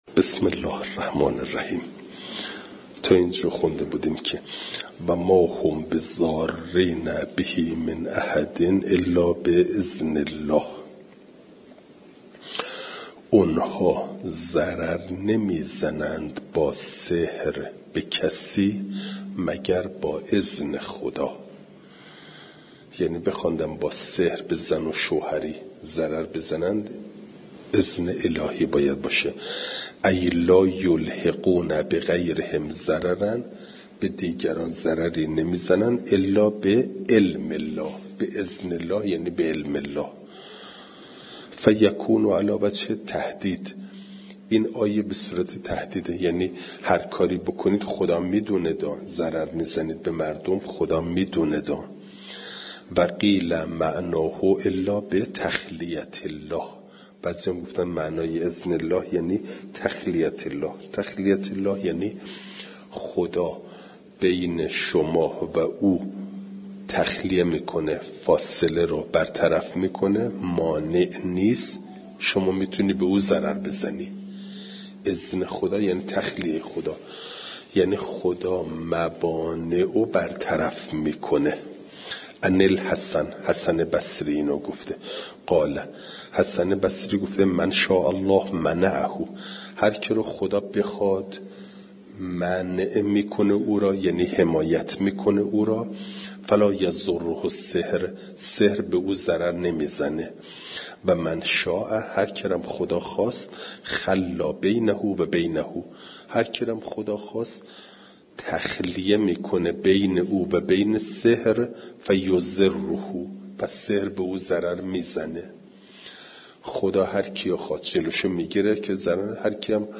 فایل صوتی جلسه صد و هفتم درس تفسیر مجمع البیان